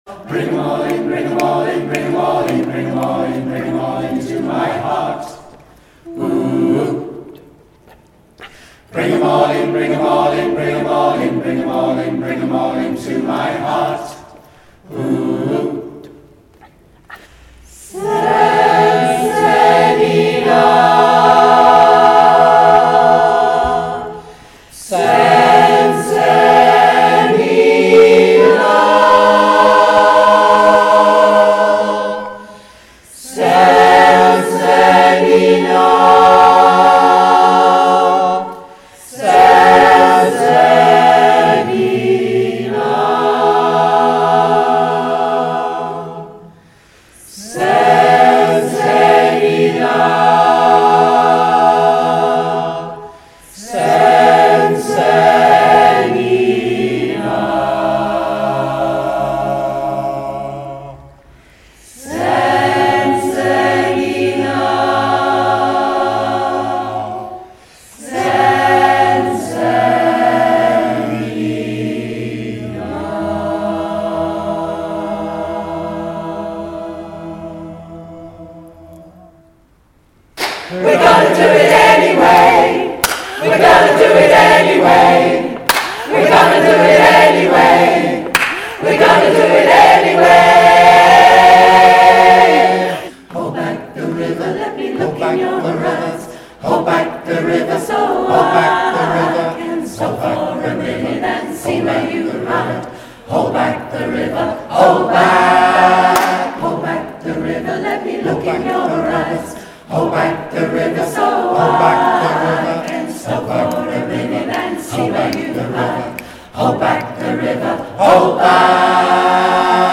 Here's a snippet from the Brockley Max gig to get you in the mood
More songs of protest and change.